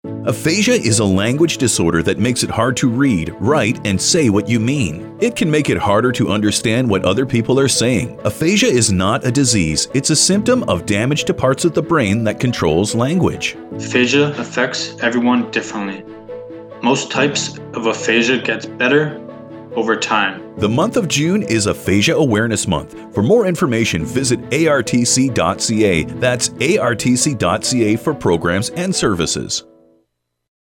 Brochure Aphasia Card Radio Commercial